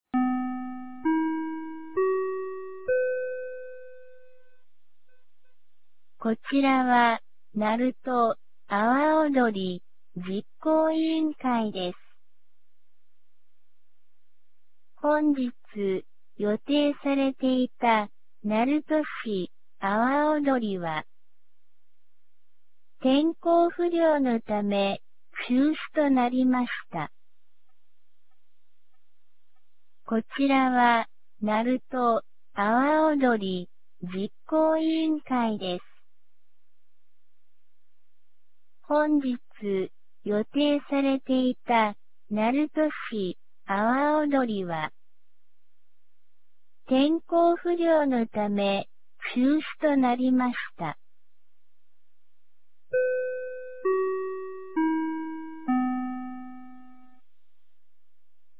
2025年08月10日 17時51分に、鳴門市より全地区へ放送がありました。
放送音声